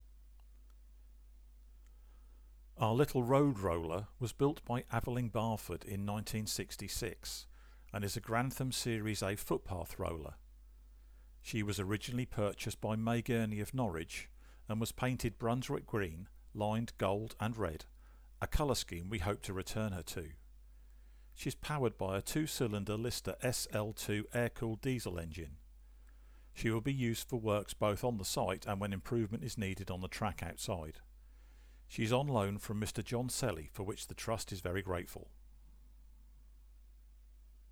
Roller
Aveling-Barford Grantham series A footpath roller of 1966